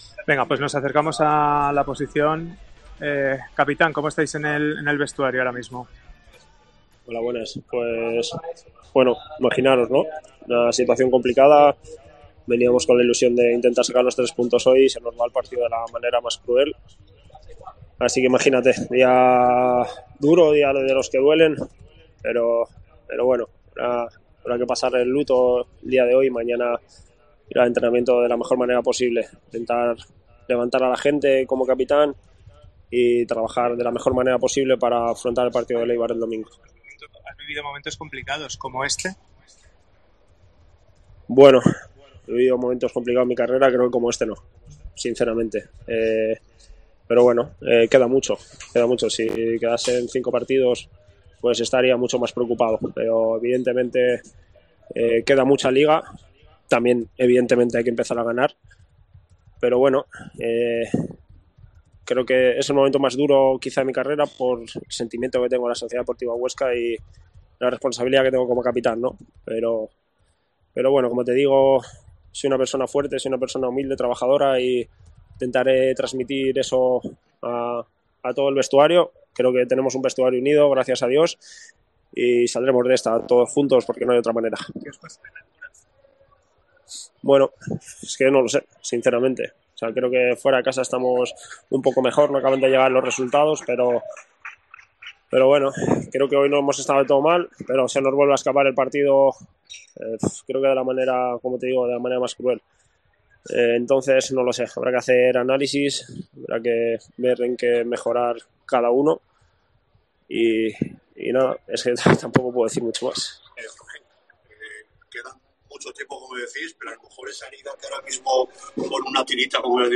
07-10-23 Declaraciones postpartido de Jorge Pulido, SD Huesca - Eldense (0-1)